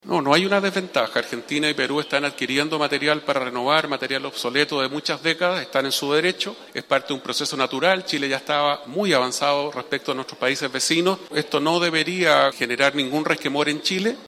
Para quien fuera ministro de Defensa en el segundo gobierno de Sebastián Piñera, el alcalde Mario Desbordes, Chile no queda en desventaja con el resto de países de la región, ya que el país estaba adelantado en la materia.